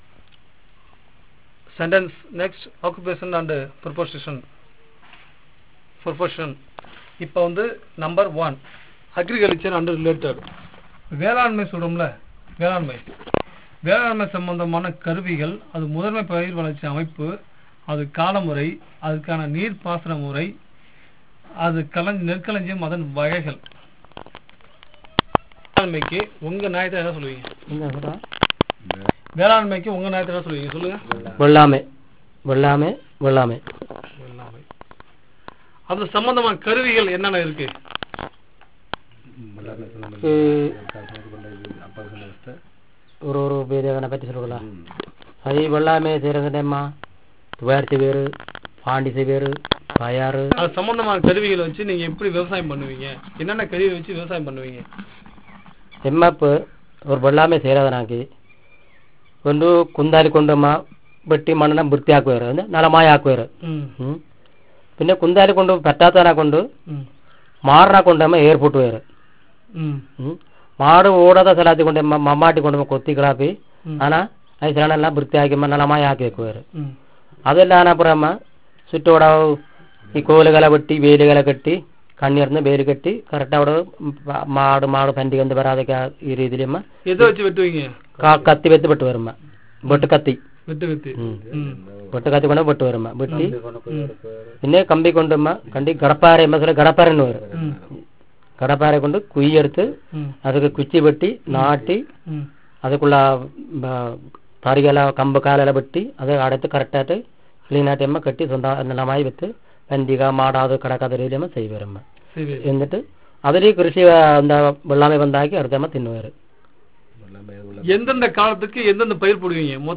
Conversation about different occupations in the community